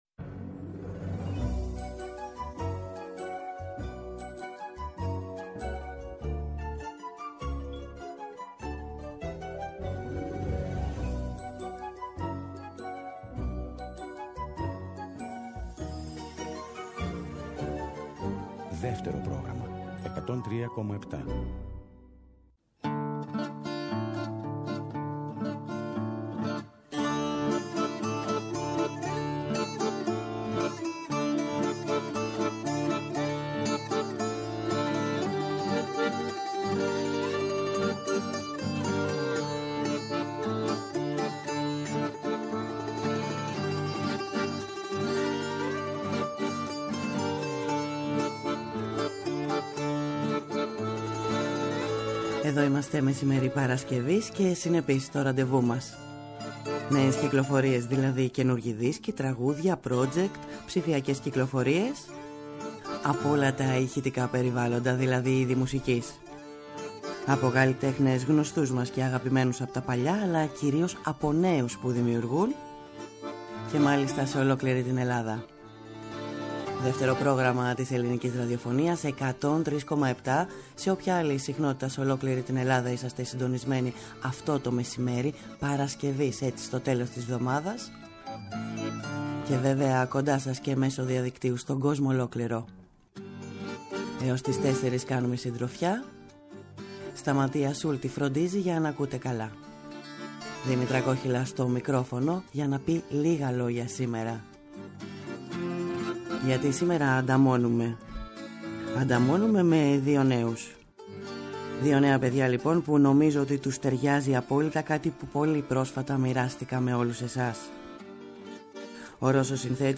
Ζωντανά στο στούντιο παρουσίασαν τραγούδια της πρώτης δισκογραφικής δουλειάς τους, αλλά και ολοκαίνουργια που τώρα ετοιμάζουν.